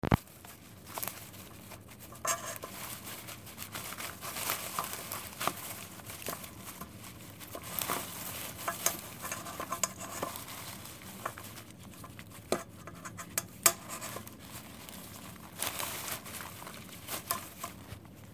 Stella’s mind raced as she heard a rustling, a rat-a-tat, and a clinking.
Then then I recorded the sound effects by holding my mobile phone in one hand and stirring a bowl of popcorn, a TV remote, and the stylus from my tablet with my other hand.